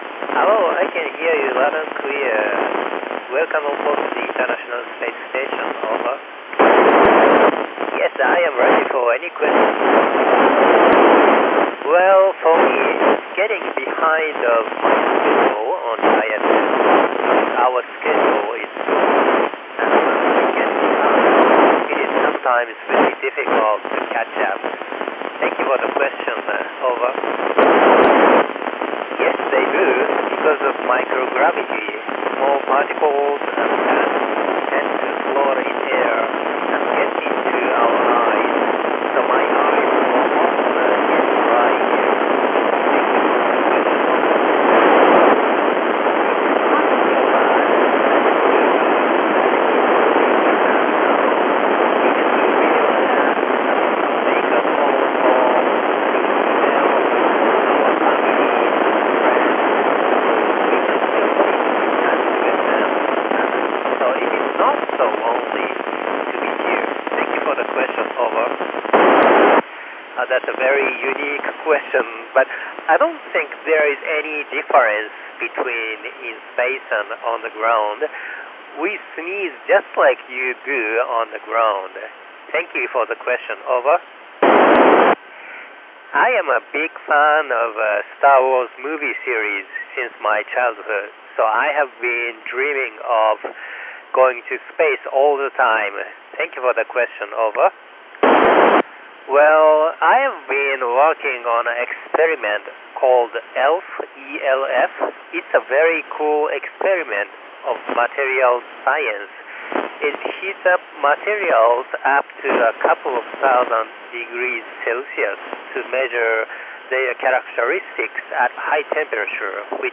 On June 4th 2025 at 12:12 UTC I monitored on 145.800 MHz in FM enclosed downlink signal of Takaya Onishi, KF5LKS using the callsign NA1SS.
Irish radio amateurs enabled the contact by building up and operating the ground station in Dublin using the callsign EI1ISS.